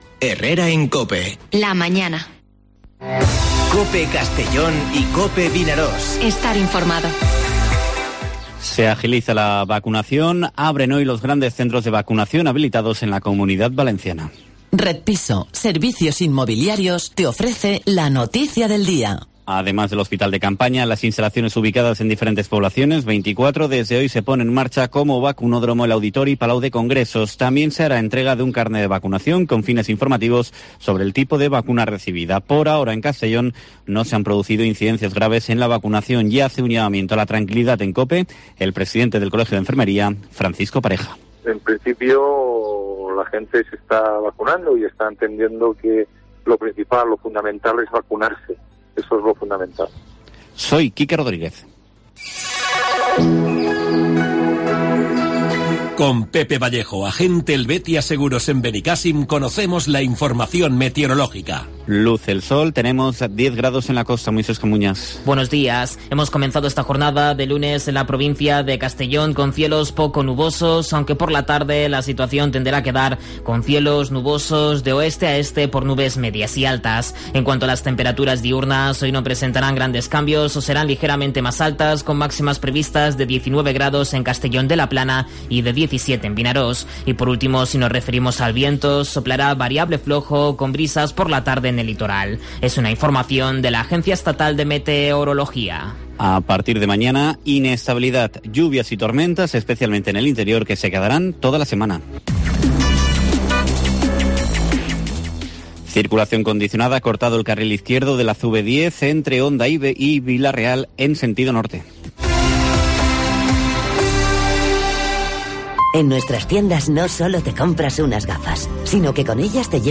Informativo Herrera en COPE en la provincia de Castellón (19/04/2021)